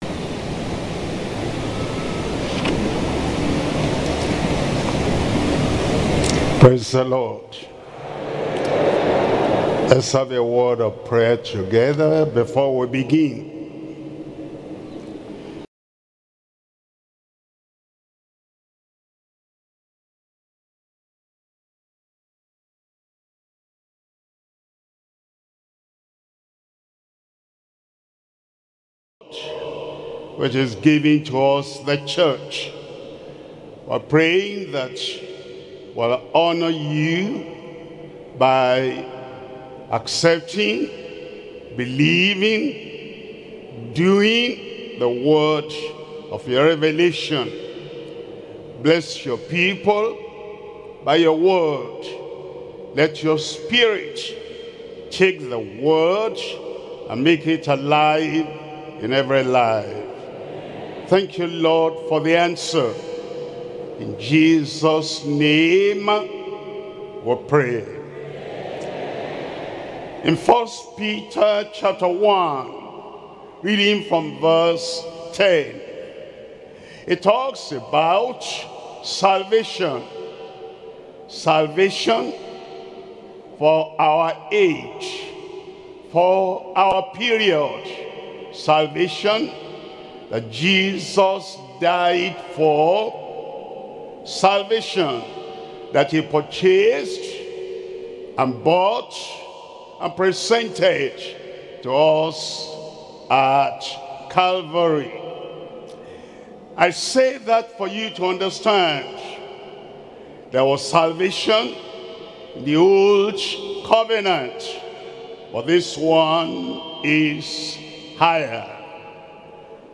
Sermons - Deeper Christian Life Ministry
2026 Global Easter Retreat